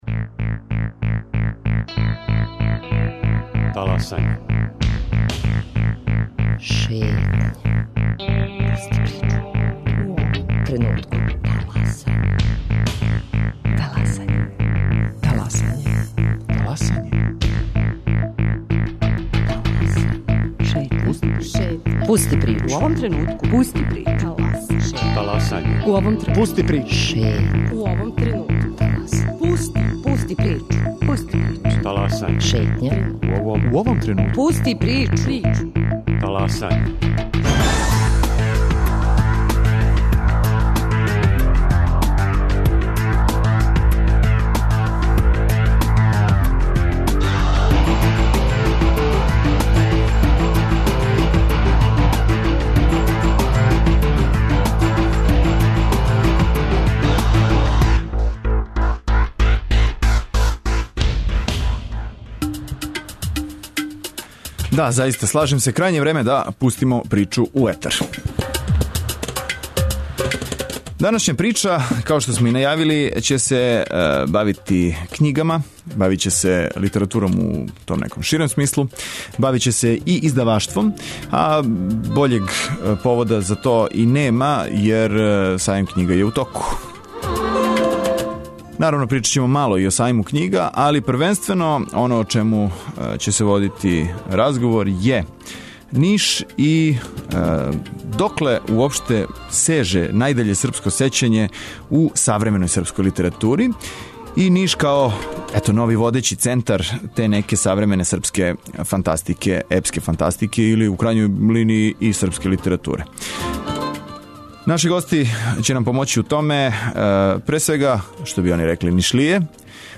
Гости: писци